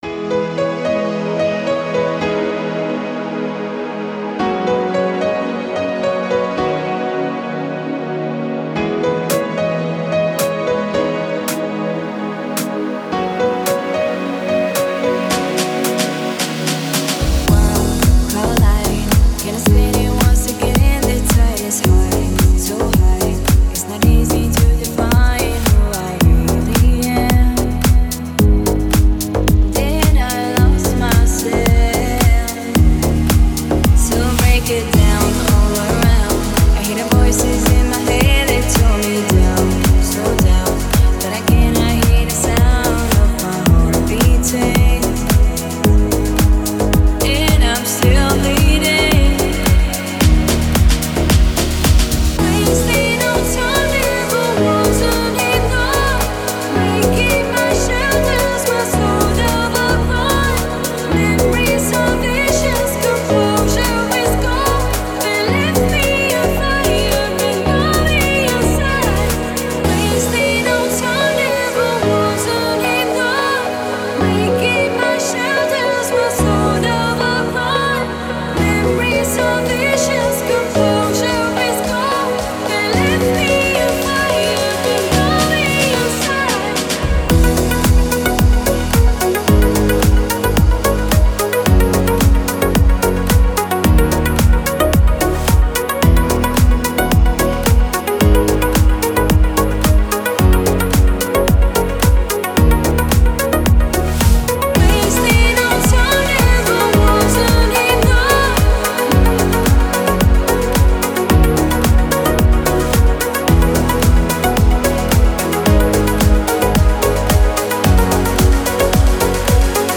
Deep House музыка
дип хаус треки